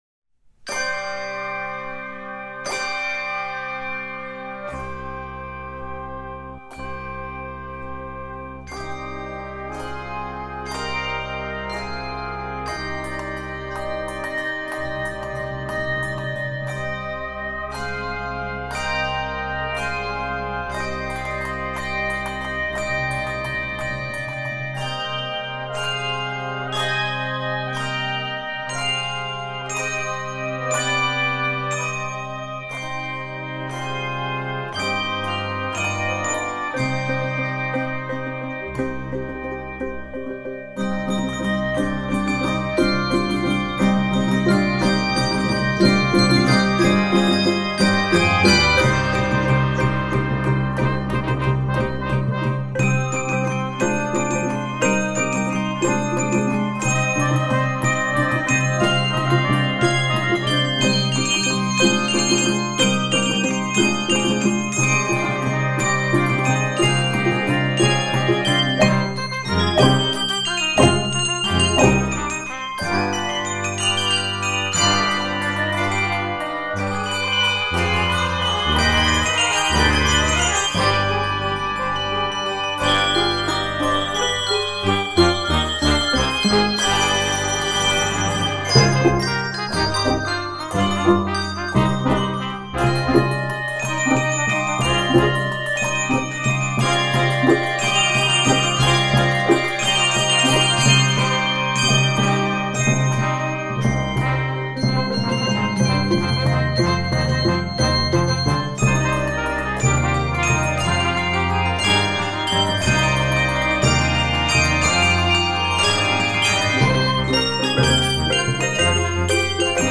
Key of c minor.